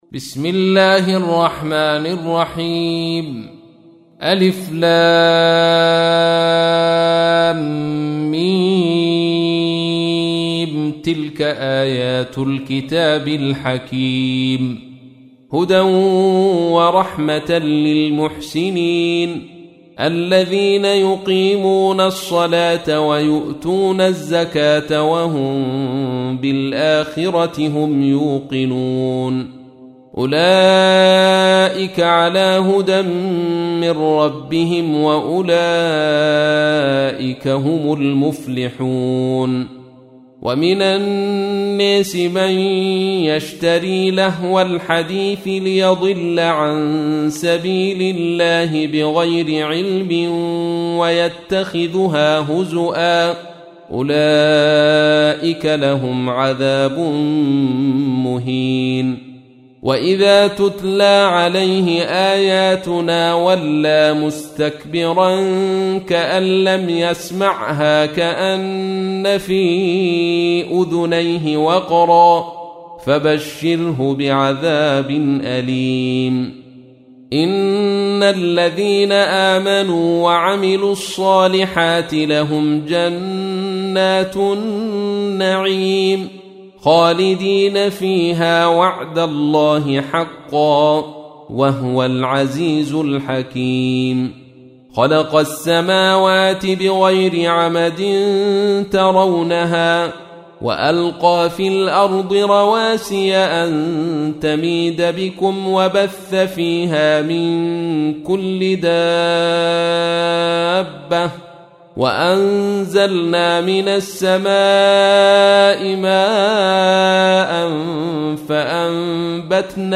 تحميل : 31. سورة لقمان / القارئ عبد الرشيد صوفي / القرآن الكريم / موقع يا حسين